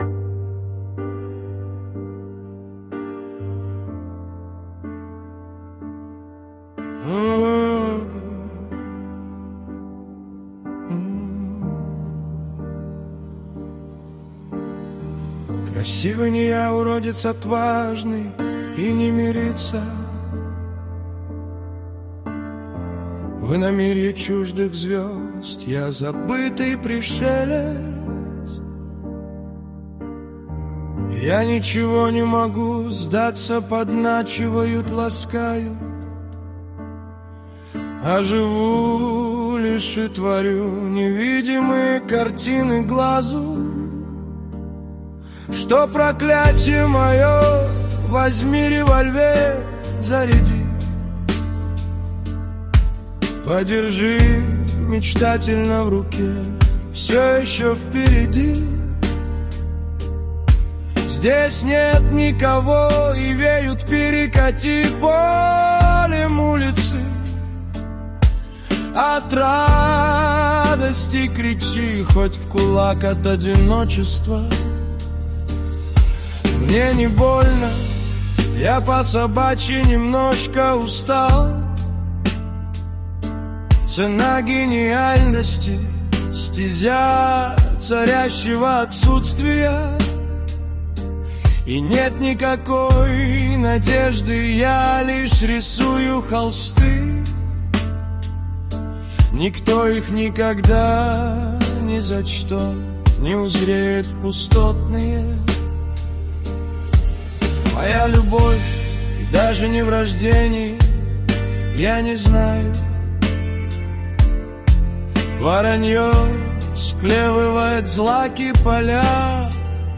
Музыка и исполнение принадлежит ИИ.
ТИП: Пісня
СТИЛЬОВІ ЖАНРИ: Драматичний